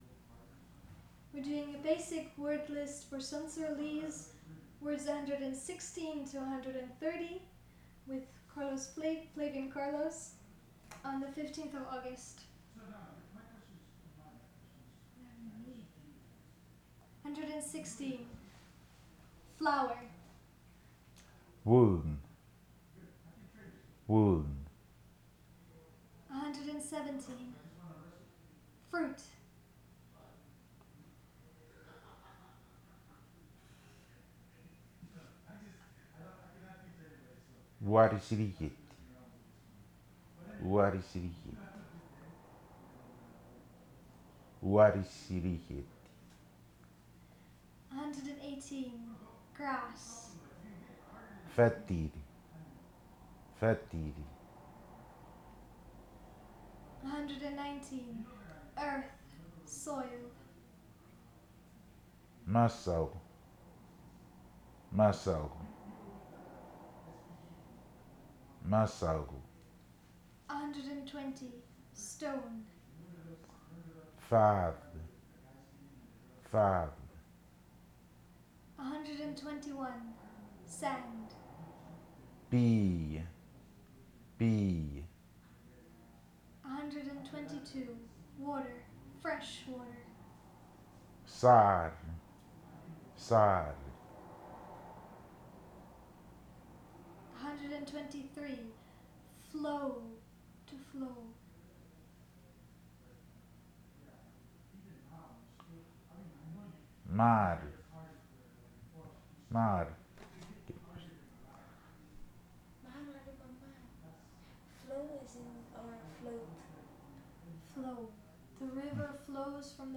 digital wav file recorded at 44.1 kHz/16 bit on Zoom H2N
Echang, Koror, Palau